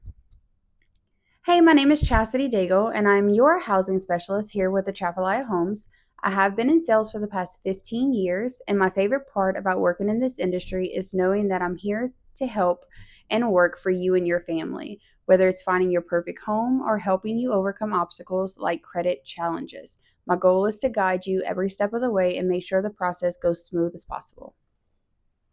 Voice Note